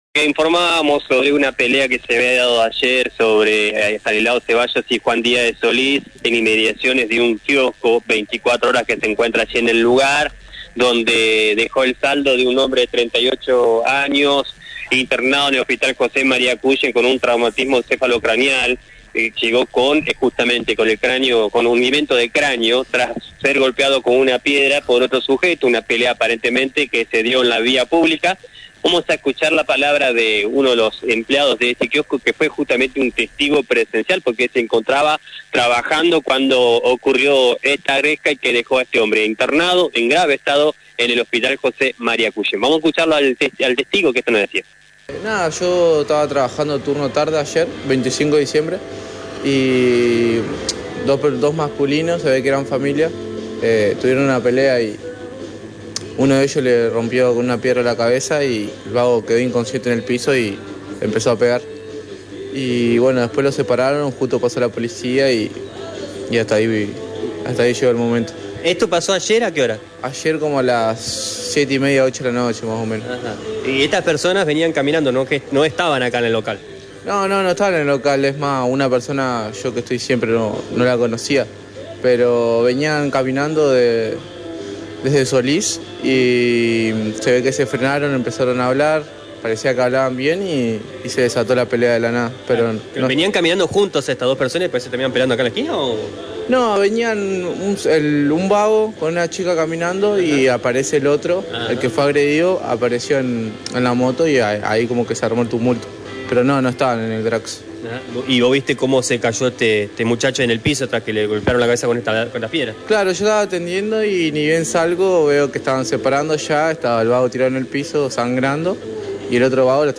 Un testigo del hecho relató lo ocurrido ante la unidad móvil de LT9